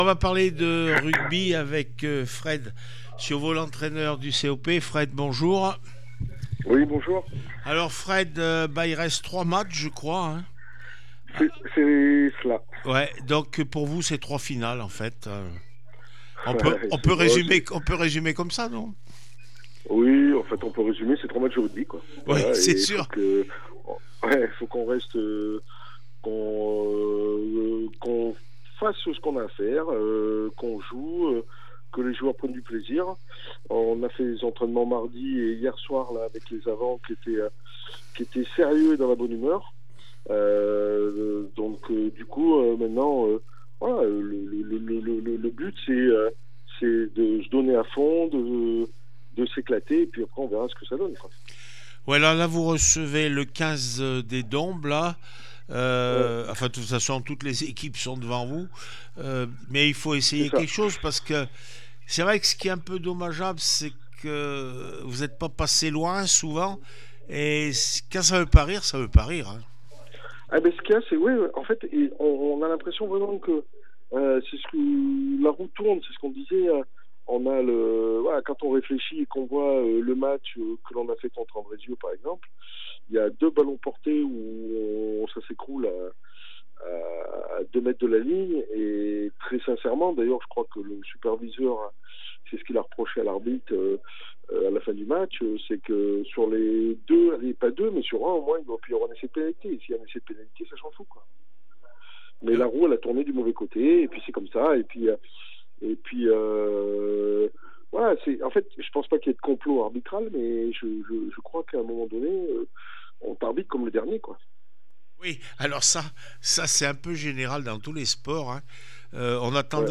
5 avril 2025   1 - Sport, 1 - Vos interviews